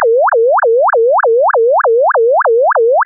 (Fig. 5e): Representative of the of the signal we should expect at the receiver when the receiver sampling frequency is 4 kHz. Although there is some bandwidth loss in comparison to A_ground_truth_fsr16k.wav, there are no aliasing/imaging artifacts. This is the intended behaviour of any auralization scheme at such low receiver sampling frequencies.